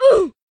oof.wav